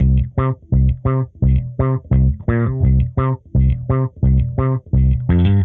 Index of /musicradar/dusty-funk-samples/Bass/85bpm